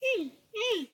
Minecraft Version Minecraft Version 1.21.5 Latest Release | Latest Snapshot 1.21.5 / assets / minecraft / sounds / mob / panda / worried / worried5.ogg Compare With Compare With Latest Release | Latest Snapshot
worried5.ogg